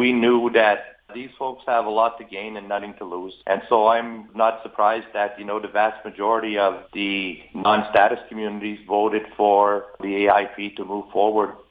He says the final tally didn’t come as much of a surprise.